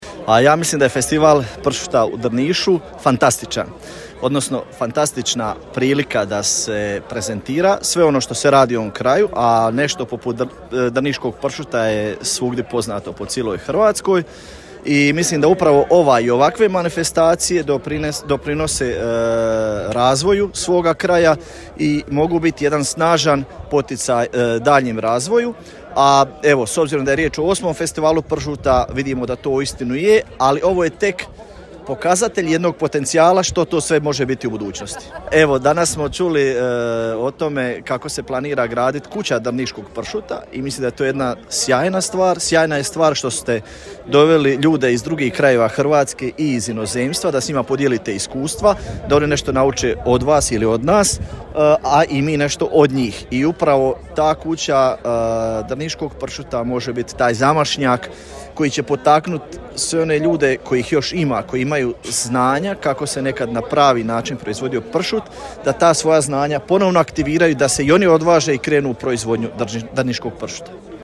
Večeras je u Drnišu svečano otvoren 8. Međunarodni festival pršuta, u organizaciji Turističke zajednice Grada Drniša te Grada Drniša.
Evo izjava:
Ravnatelj Uprave za regionalni razvoj Ivan Bota